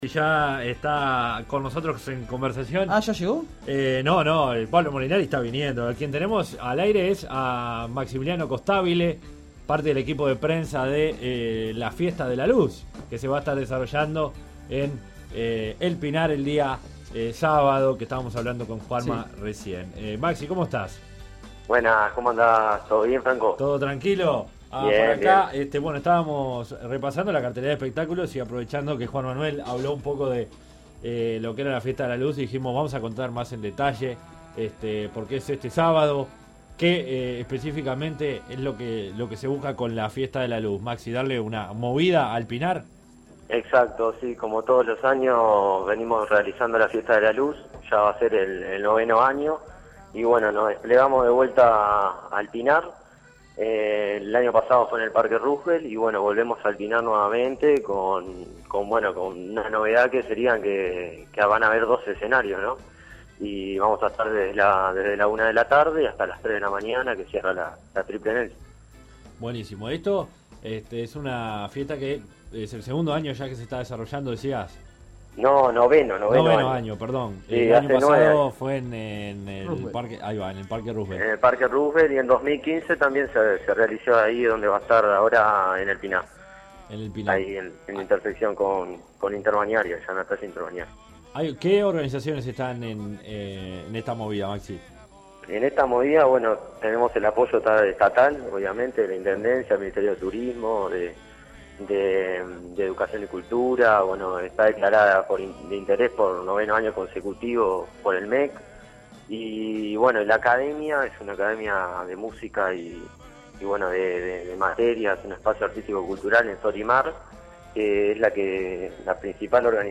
habló sobre el evento en Creer o Reventar: